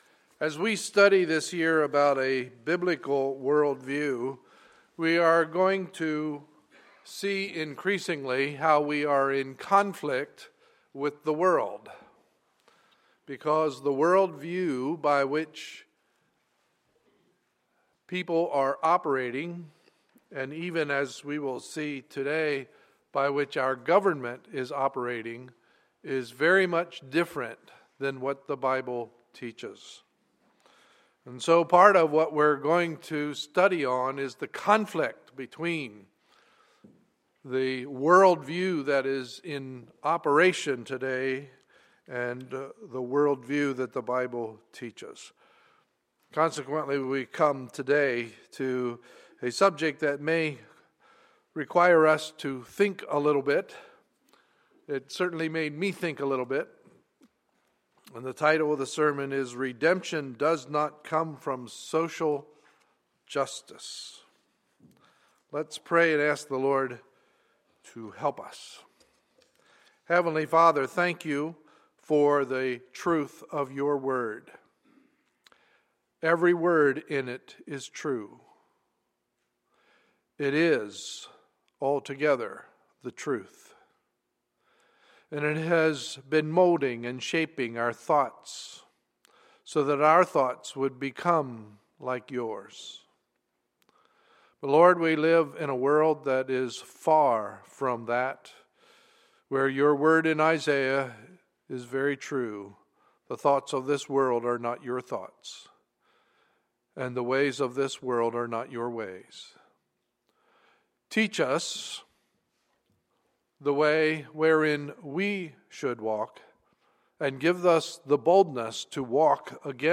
Sunday, March 2, 2014 – Morning Service